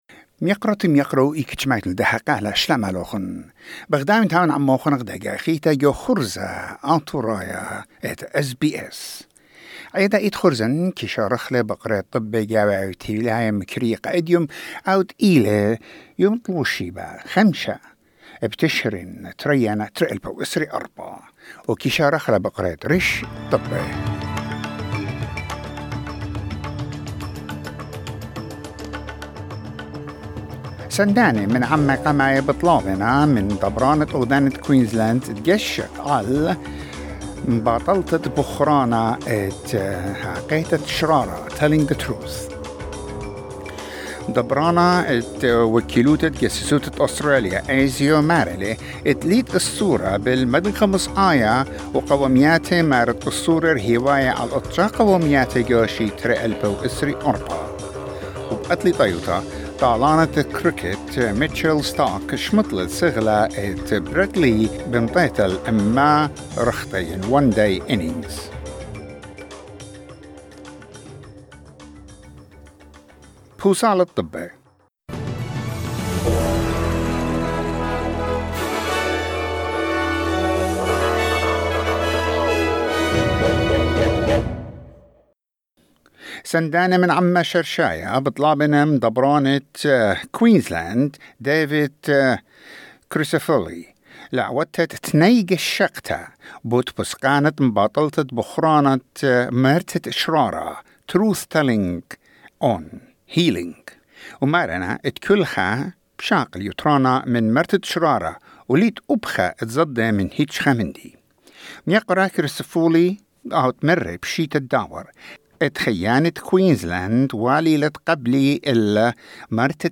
SBS Assyrian news bulletin: 5 November 2024